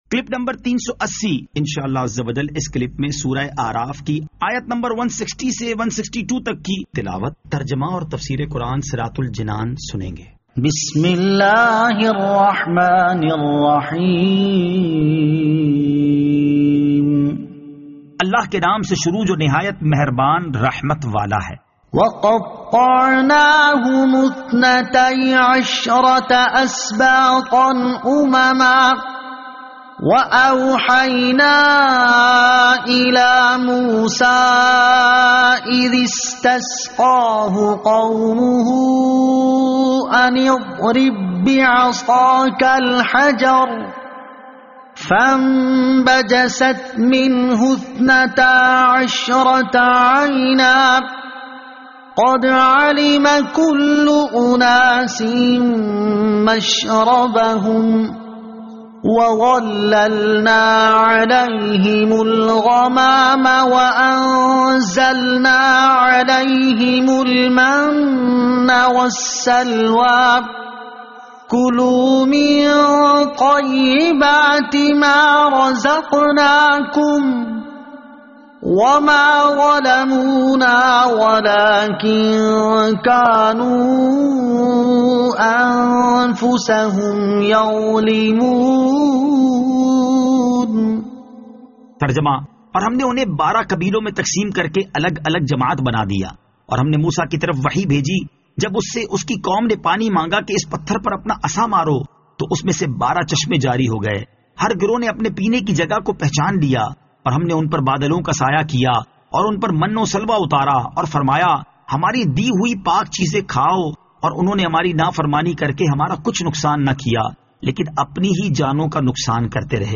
Surah Al-A'raf Ayat 160 To 162 Tilawat , Tarjama , Tafseer